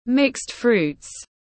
Trái cây thập cẩm tiếng anh gọi là mixed fruits, phiên âm tiếng anh đọc là /mɪkst fruːt/
Mixed fruits /mɪkst fruːt/